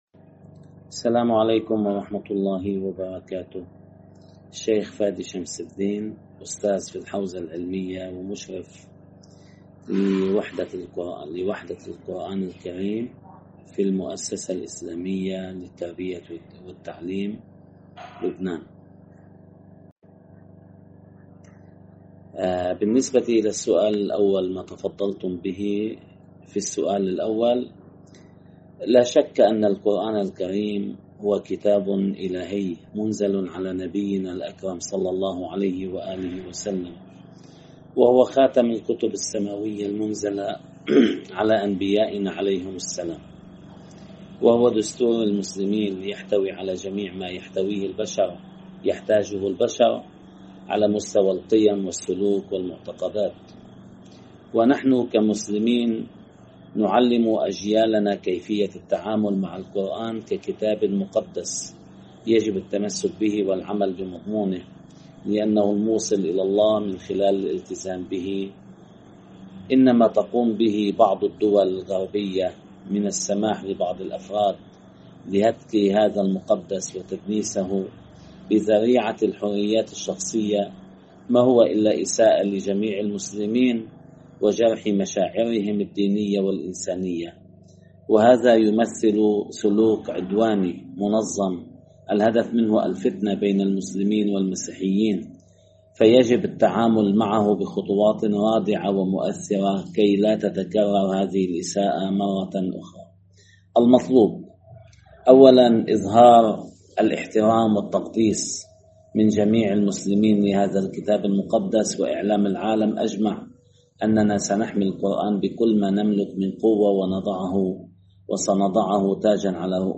عالم دين لبناني لـ"إکنا":